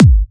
VEC3 Bassdrums Trance 16.wav